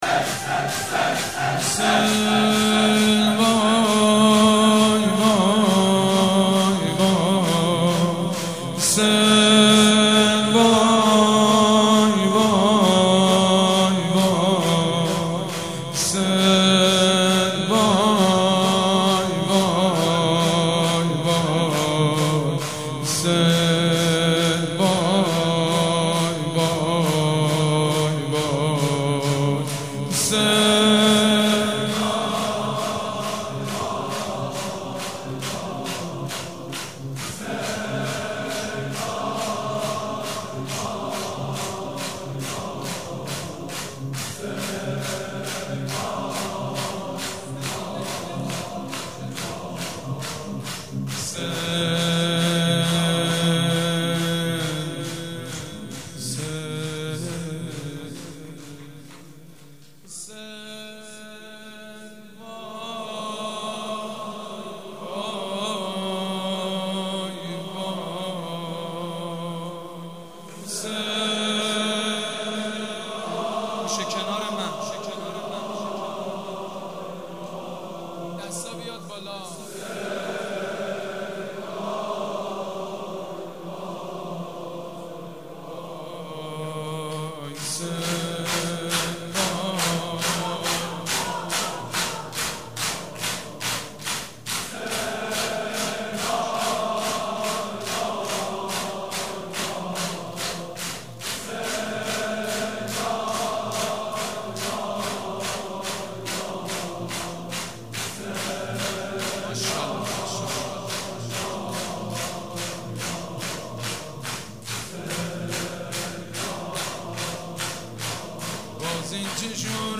شب اول محرم الحرام
شور
مداح
حاج سید مجید بنی فاطمه
مراسم عزاداری شب اول